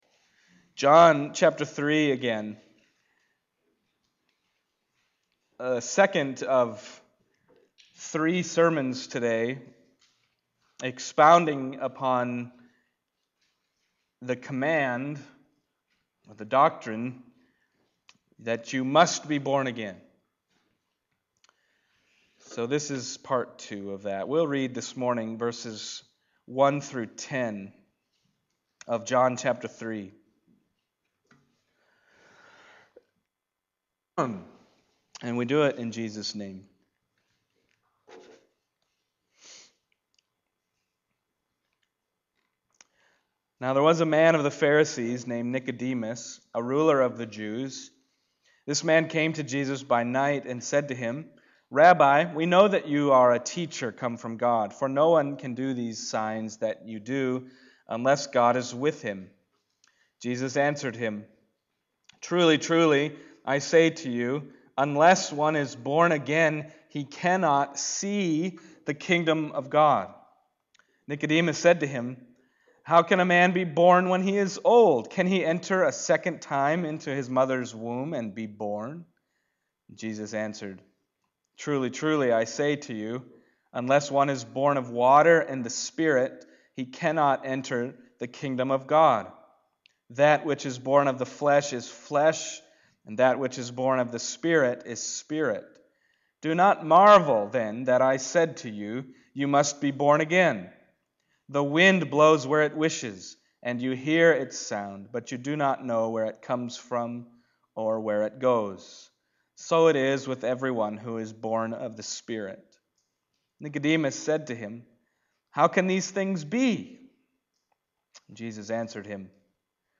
John Passage: John 3:1-10 Service Type: Sunday Morning John 3:1-10 « You Must Be Born Again